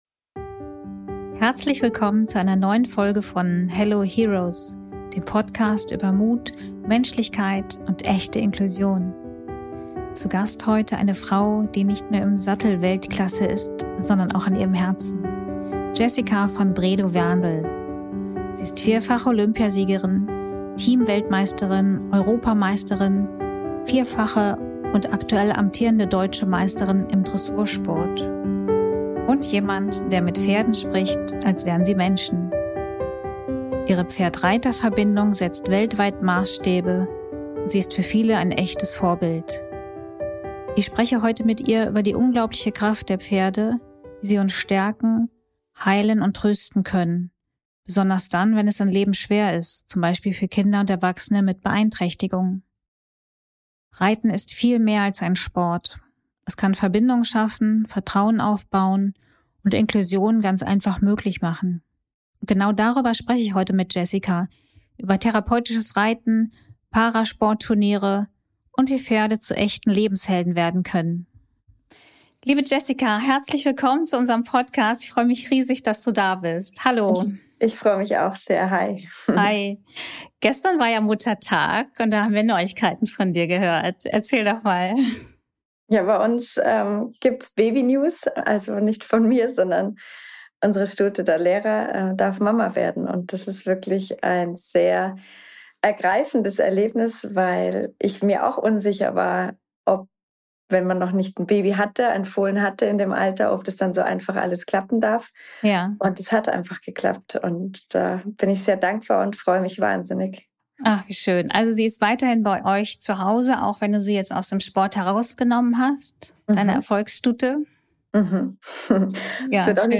Beschreibung vor 10 Monaten In dieser besonderen Folge von Hello Heroes spreche ich mit Olympiasiegerin Jessica von Bredow-Werndl über die tiefe Verbindung zwischen Mensch und Pferd.
Wir sprechen über therapeutisches Reiten, emotionale Heilung und die Bedeutung echter Inklusion im Reitsport. Ein ehrliches, emotionales und inspirierendes Gespräch mit einer der beeindruckendsten Persönlichkeiten der Pferdewelt und Botschafterin von Horses for Heroes: Jessica von Bredow Werndl.